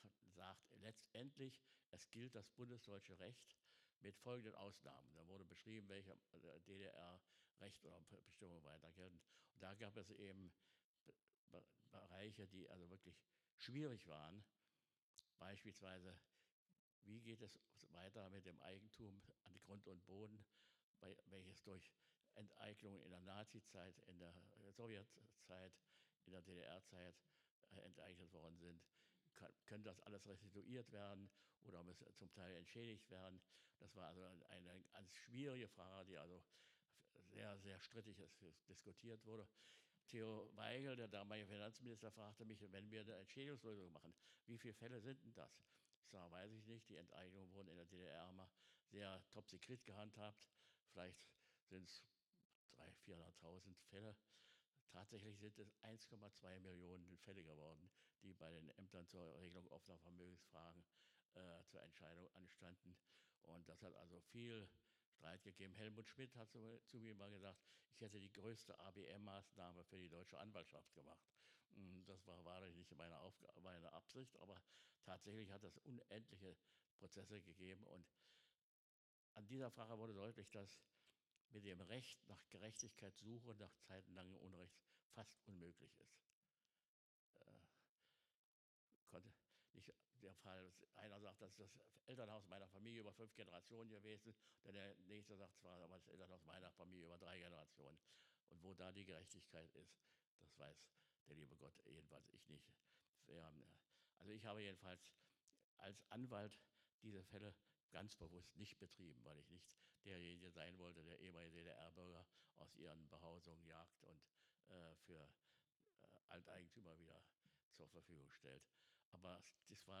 Rede von Lothar de Maizière Teil II